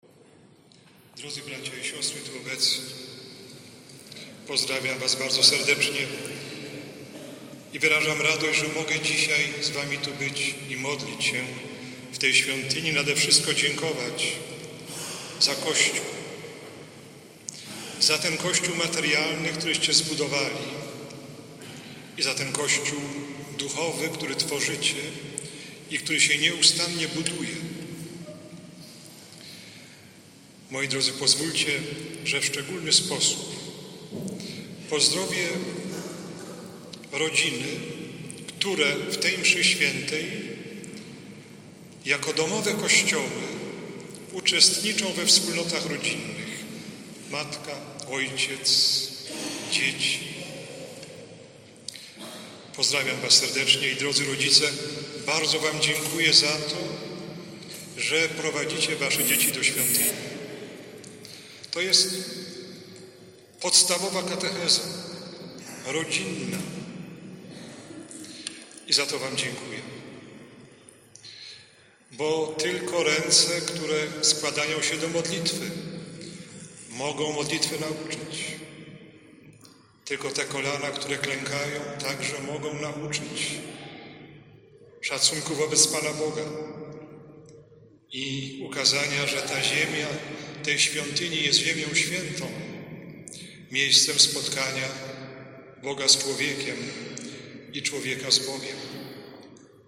Uroczystość poświęcenia nowego kościoła w Starym Sączu.
Posłuchaj abp Wiktora Skworca: